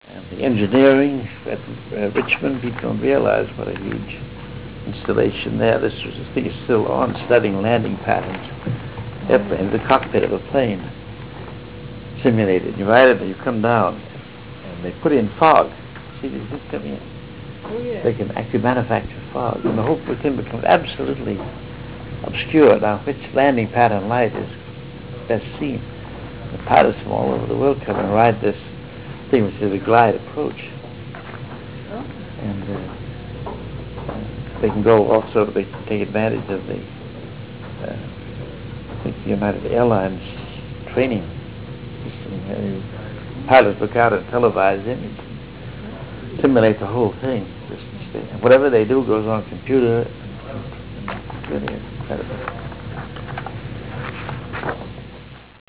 446Kb Ulaw Soundfile Hear Ansel Adams discuss this photo: [446Kb Ulaw Soundfile]